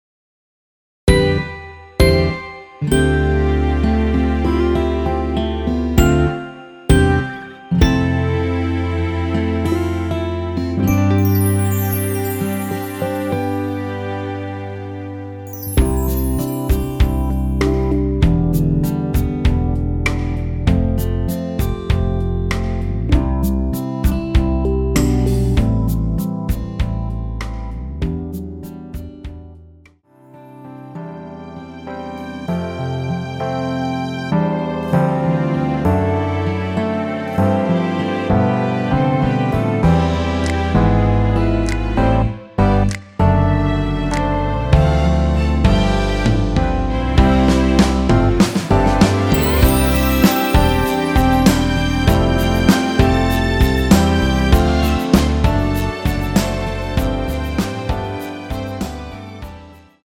원키에서(-2)내린 MR입니다.
음원 퀄리티 좋습니다! 잘 쓸게요!!
앞부분30초, 뒷부분30초씩 편집해서 올려 드리고 있습니다.
중간에 음이 끈어지고 다시 나오는 이유는